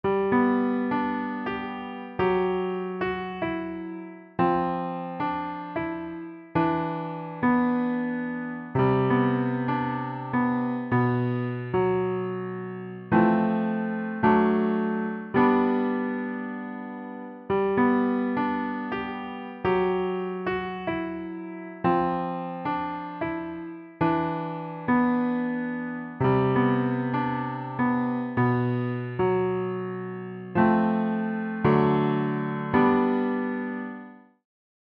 Improvize - Piano Music, Solo Keyboard
A piano music I improvised I hope you like it.